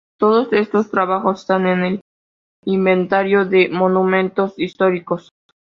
Uitgesproken als (IPA)
/imbenˈtaɾjo/